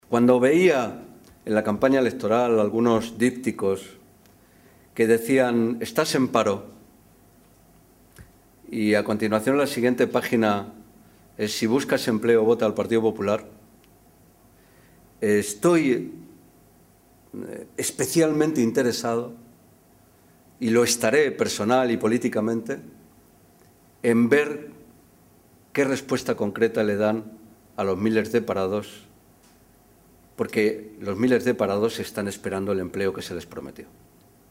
Fernando Moraleda, diputado nacional del PSOE
Cortes de audio de la rueda de prensa